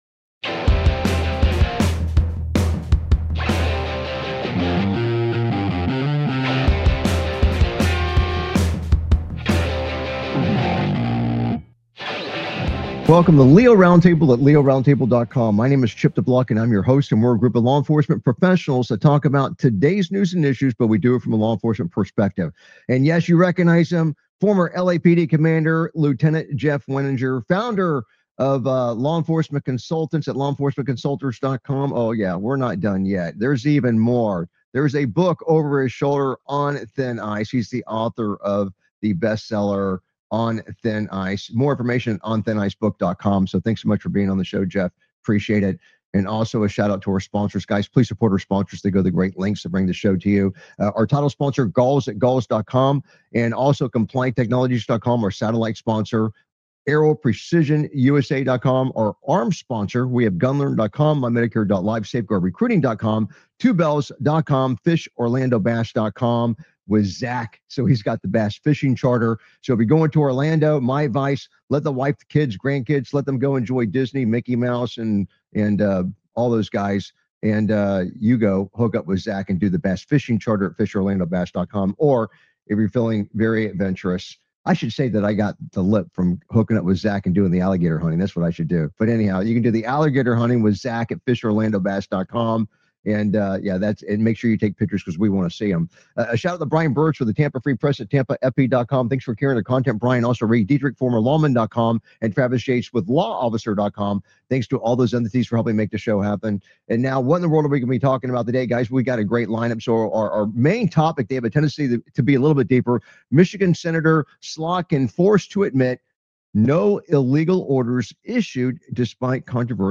Talk Show Episode, Audio Podcast, LEO Round Table and S10E235, Cop Acquitted For The Death Of Woman Who Almost Hit Him With Her Car on , show guests , about Cop Acquitted For The Death Of Woman, categorized as Entertainment,Military,News,Politics & Government,National,World,Society and Culture,Technology,Theory & Conspiracy